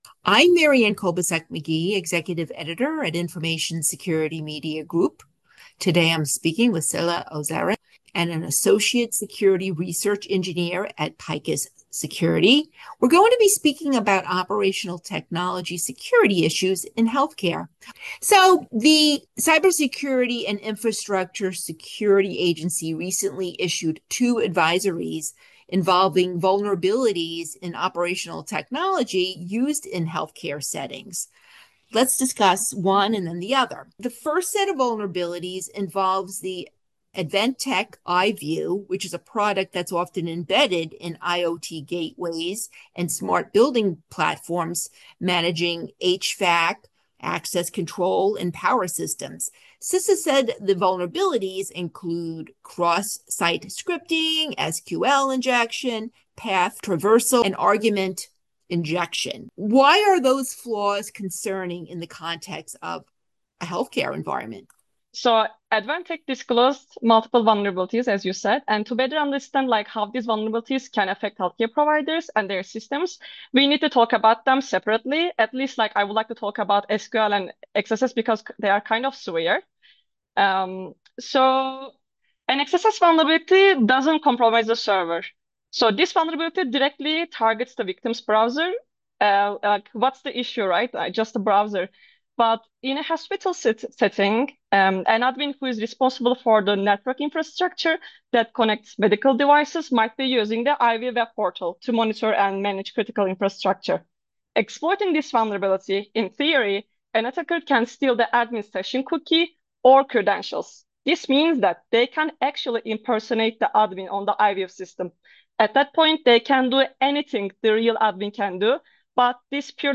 Audio interviews with information security professionals.